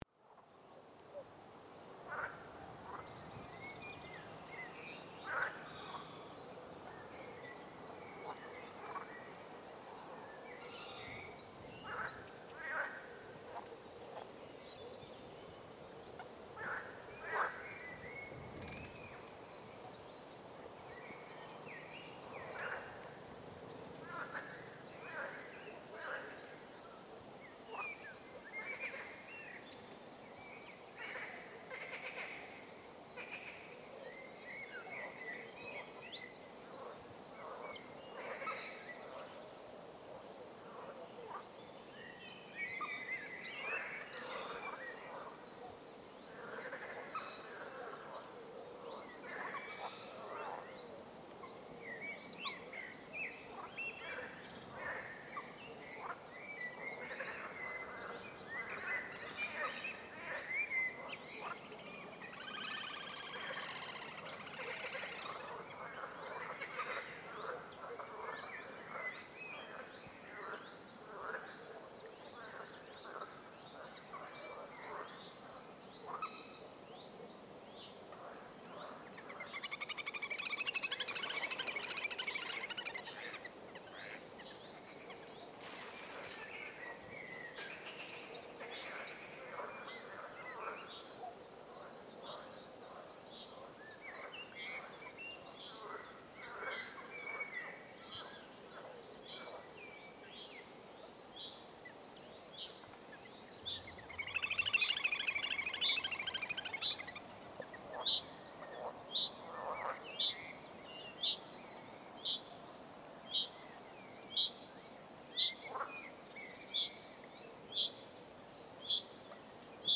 Biophonia
Biophony guaranteed here: frogs, crickets and birds sing their hearts out in the balmy late spring evening.